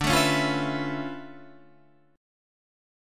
D Minor Major 13th